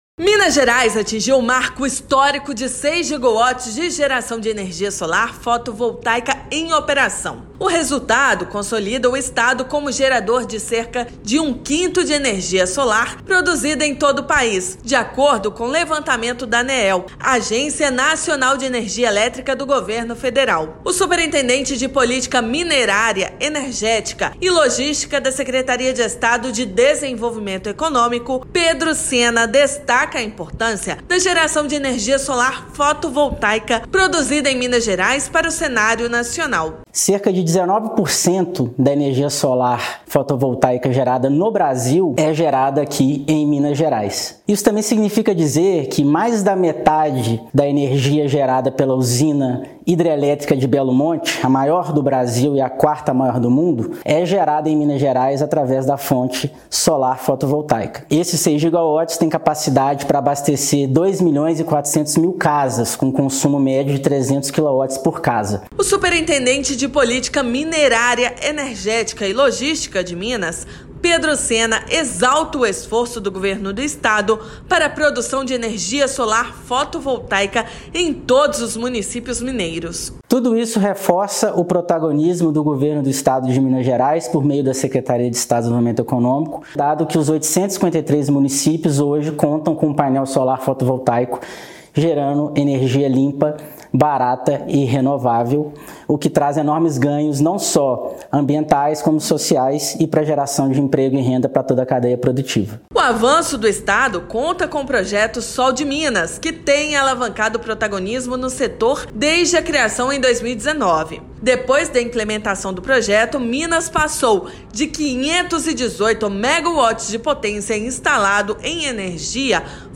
Marco histórico coloca o Estado como responsável por mais de 19% da geração de toda energia solar do país. Ouça matéria de rádio.